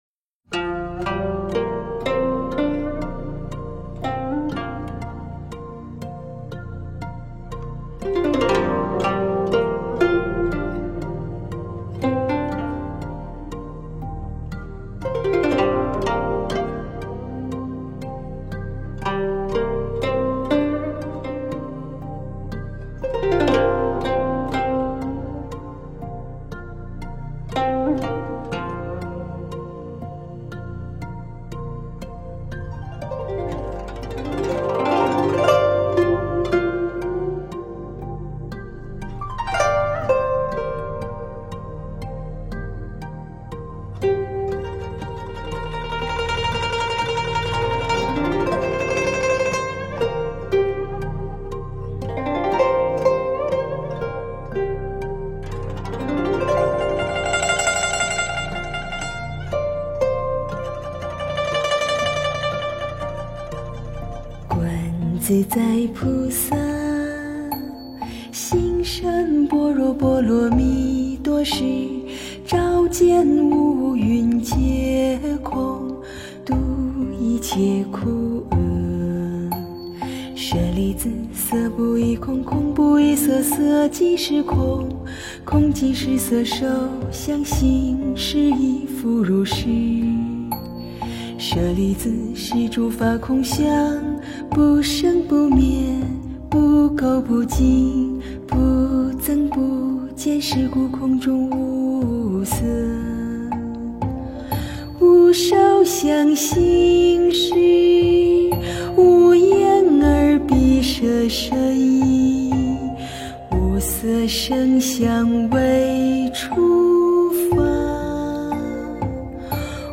诵经
佛音 诵经 佛教音乐 返回列表 上一篇： 三宝歌 下一篇： 心经 相关文章 梵网经-十地-体性平等地 梵网经-十地-体性平等地--未知...